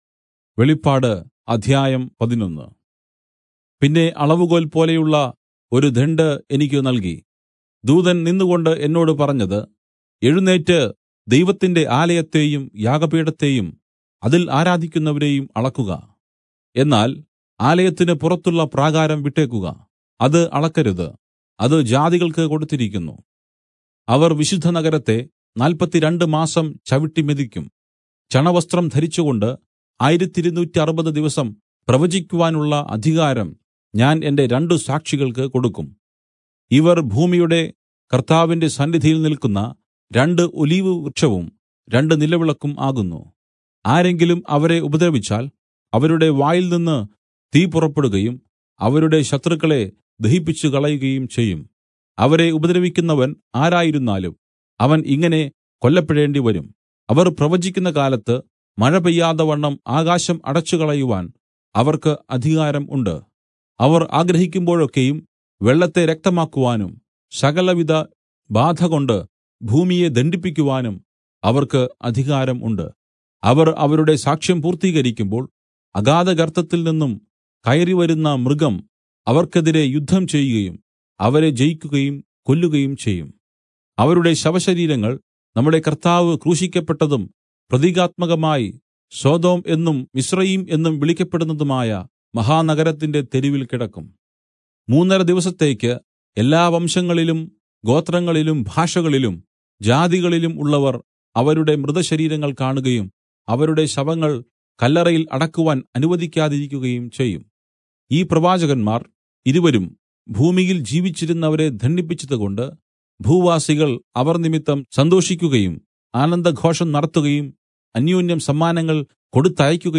Malayalam Audio Bible - Revelation 4 in Irvml bible version